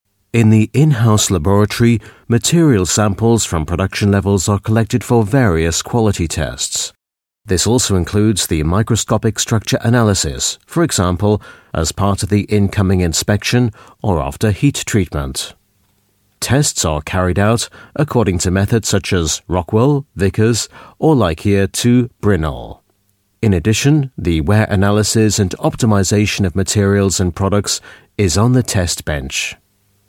Native Speaker
Englisch (UK)
Imagefilme